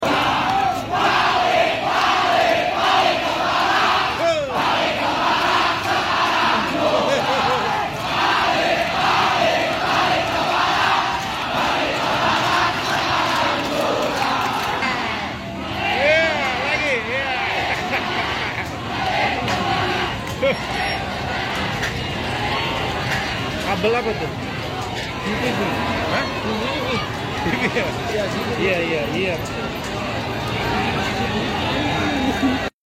Aksi massa mahasiswa meminta TNI sound effects free download
Aksi massa mahasiswa meminta TNI kembali ke berak pada Jumat, 29 Agustus 2025, di depan gedung Polda Metro Jaya di Jakarta Selatan.